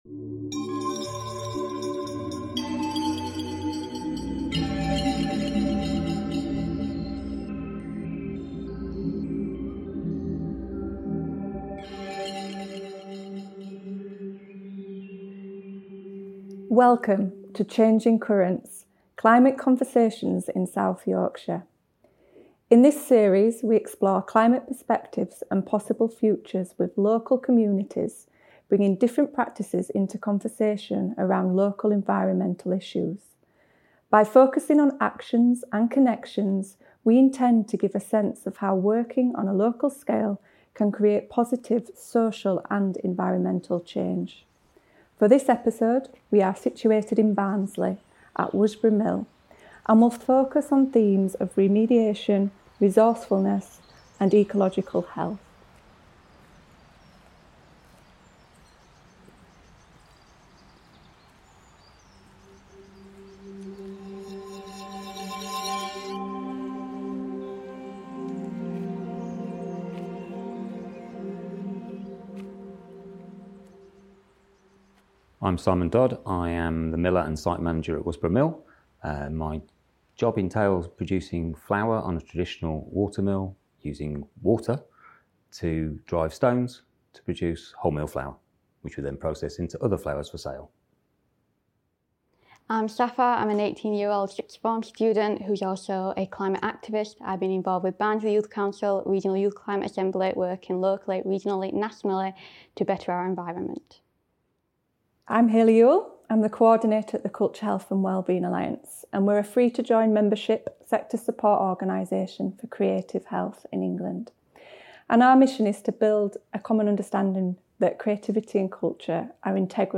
Changing Currents is a new podcast series exploring climate perspectives and possible futures featuring the voices of artists, growers, activists, local community groups, heritage workers and researchers across South Yorkshire.
This recording took place at Worsbrough Mill in Barnsley.